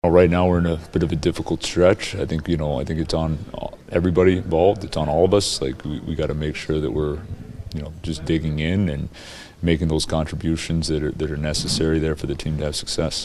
Muse says the Penguins have to work to get out of their slump.
nws0581-dan-muse-man-we-suck.mp3